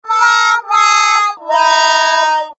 target_Lose.ogg